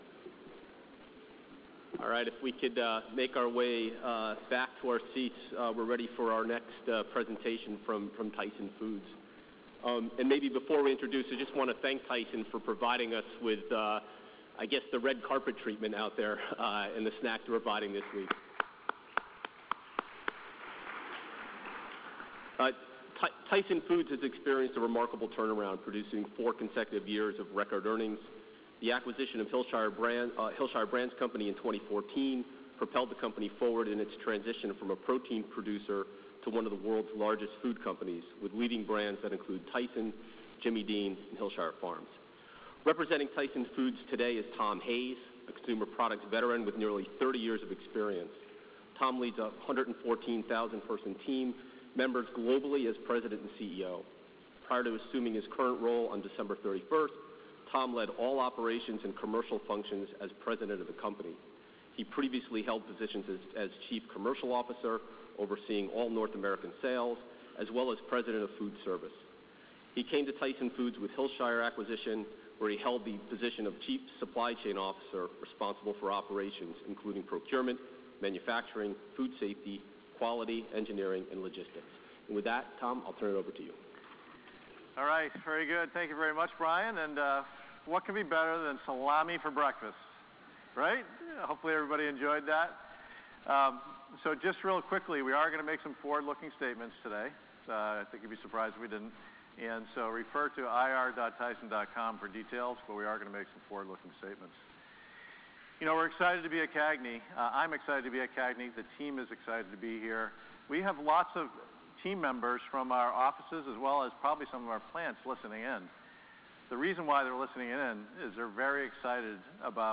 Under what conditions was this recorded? Tyson Foods Inc. - Tyson Presentation at Consumer Analyst Group of New York 2017